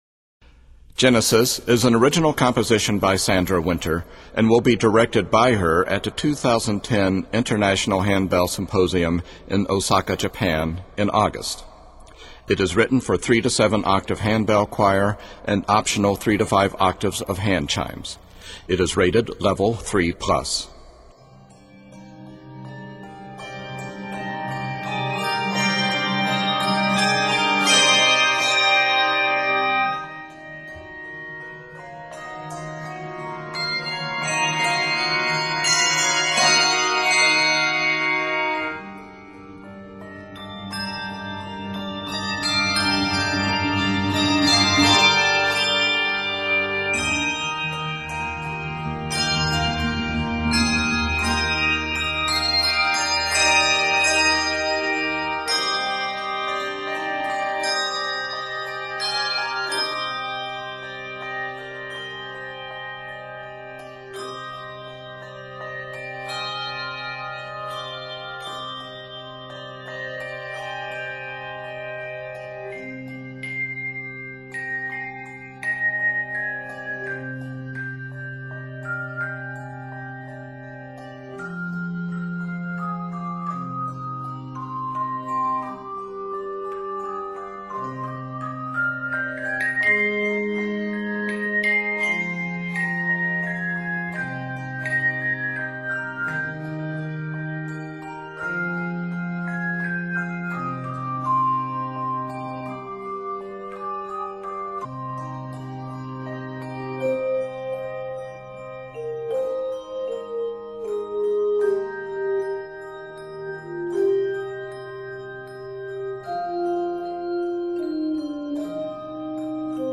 N/A Octaves: 3-7 Level